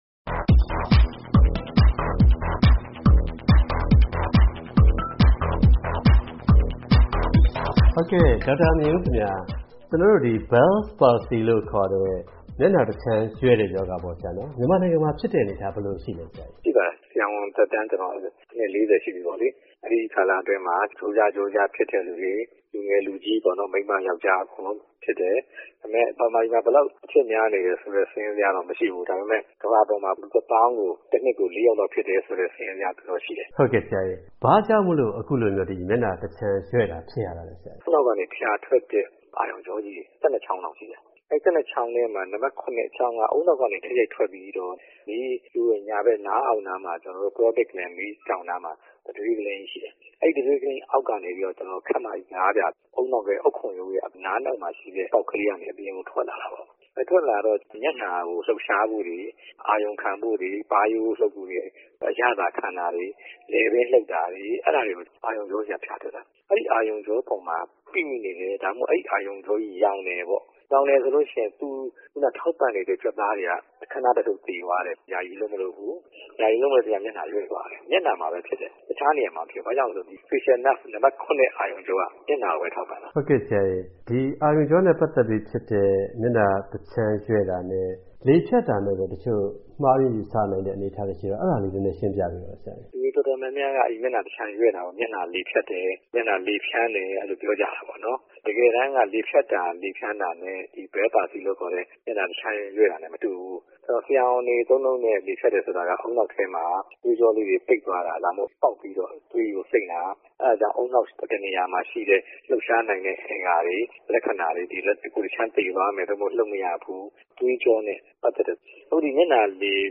ဆက်သွယ်မေးမြန်းတင်ပြထားပါတယ်။